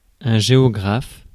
Prononciation
Prononciation France: IPA: [ʒeɔ.gʁaf] Accent inconnu: IPA: /ʒe.o.ɡʁaf/ Le mot recherché trouvé avec ces langues de source: français Traduction 1.